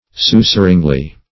susurringly - definition of susurringly - synonyms, pronunciation, spelling from Free Dictionary Search Result for " susurringly" : The Collaborative International Dictionary of English v.0.48: Susurringly \Su*sur"ring*ly\, adv. In the manner of a whisper.